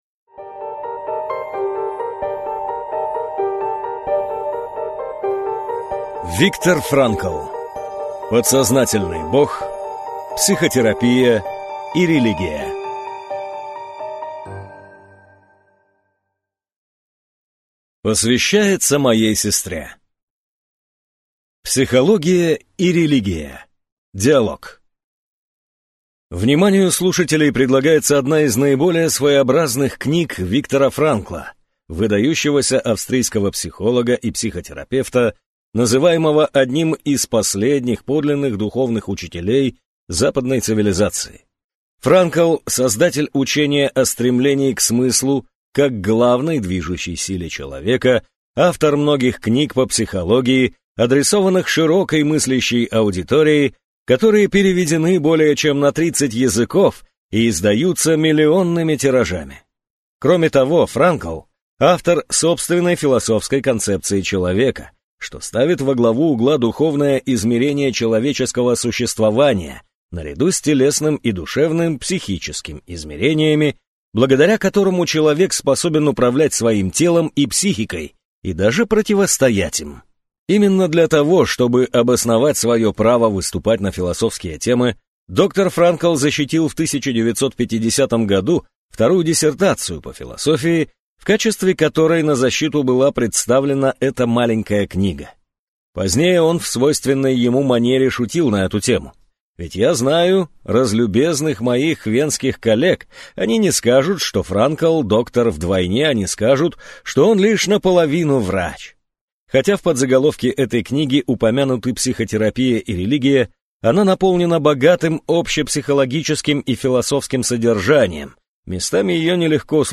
Аудиокнига Подсознательный бог: Психотерапия и религия | Библиотека аудиокниг